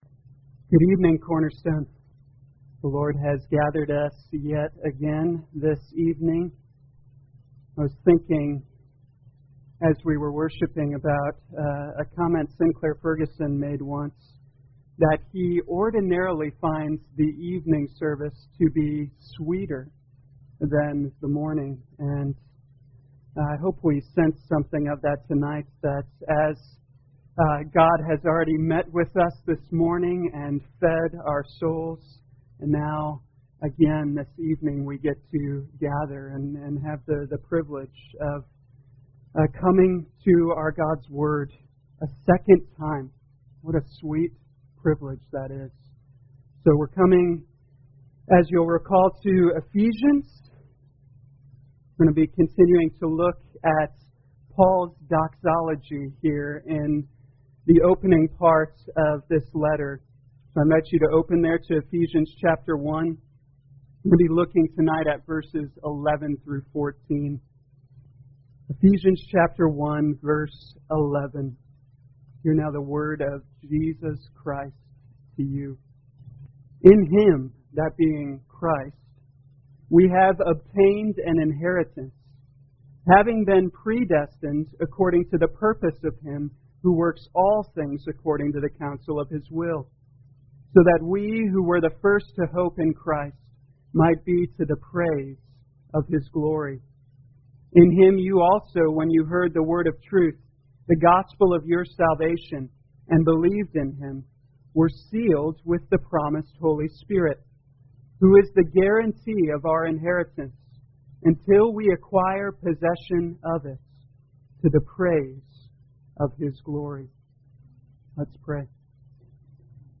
2020 Ephesians Evening Service Download